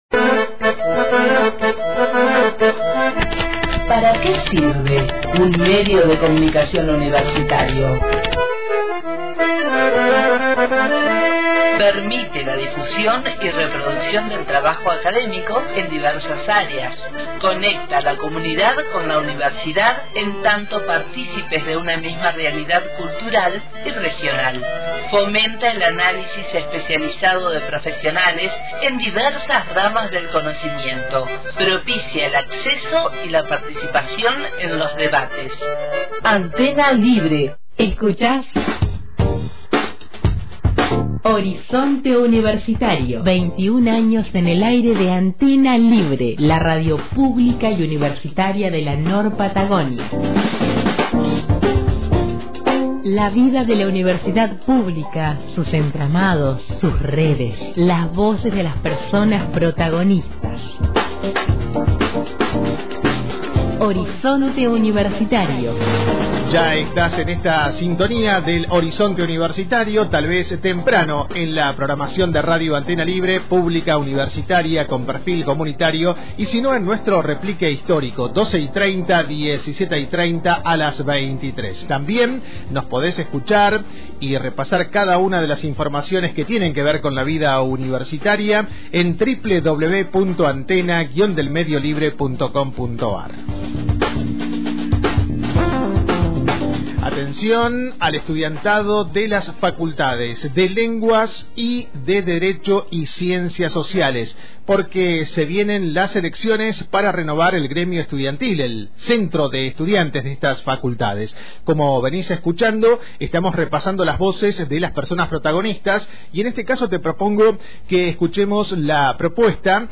A una semana del inicio de los comicios, en «Horizonte Universitario» conversamos con